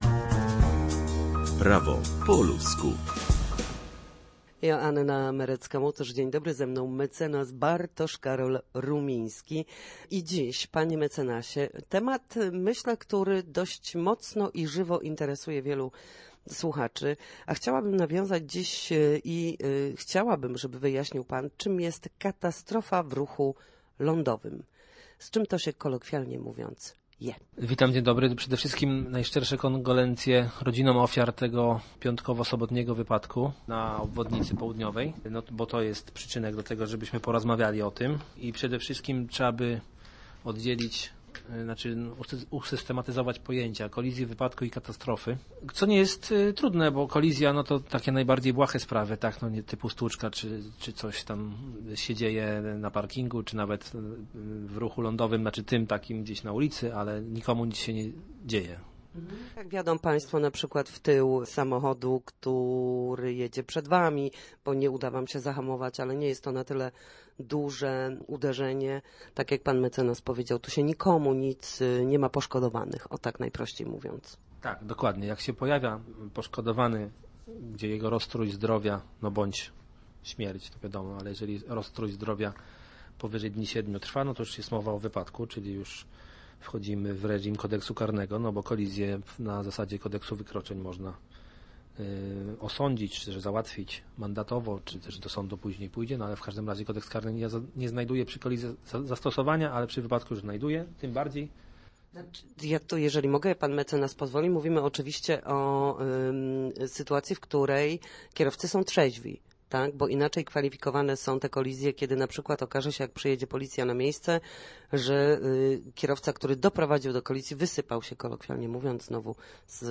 Posłuchajcie całej rozmowy, która odnosi się do katastrofy, do której doszło z piątku na sobotę w Trójmieście: